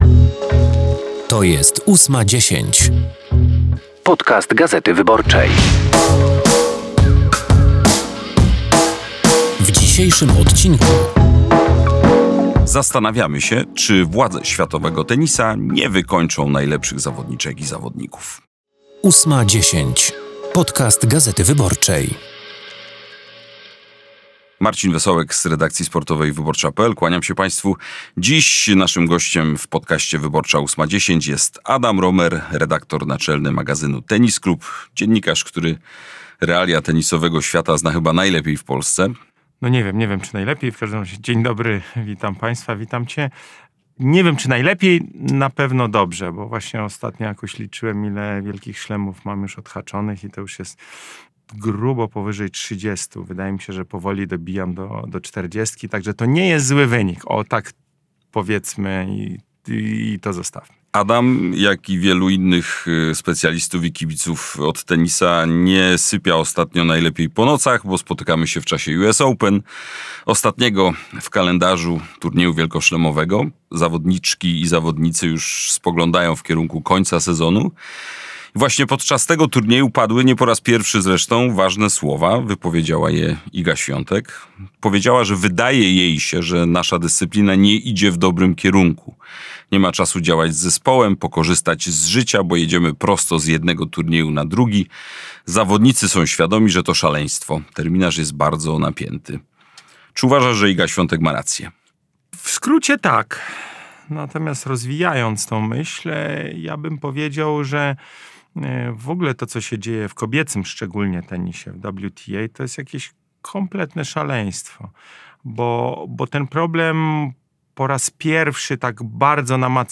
rozmawia z Cezarym Tomczykiem, wiceministrem obrony narodowej i wiceprzewodniczącym Platformy Obywatelskiej, o szczegółach akcji pomocowej "Feniks" na terenach popowodziowych. Jaka jest wartość zniszczeń spowodowanych przez powódź? Ilu żołnierzy jest zaangażowanych w akcję pomocową? Jak na zalanych terenach wygląda pomoc medyczna?